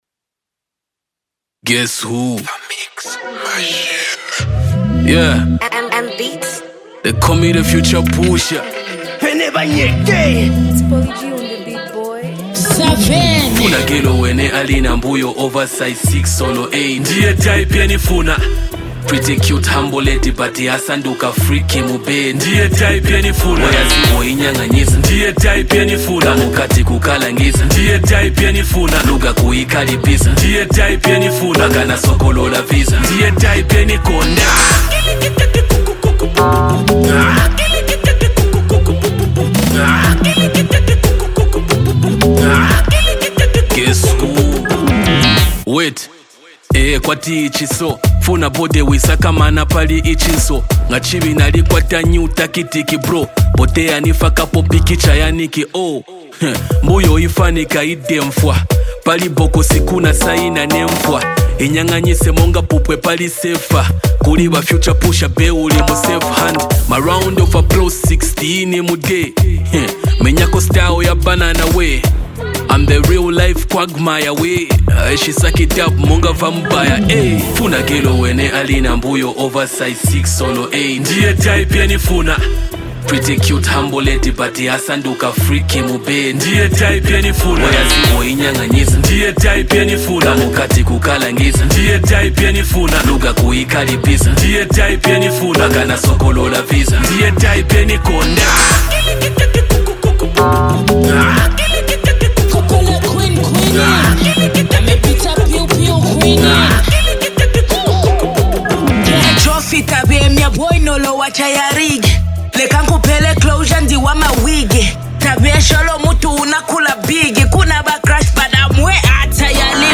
a bold and empowering female perspective
a raw, street-rooted rap verse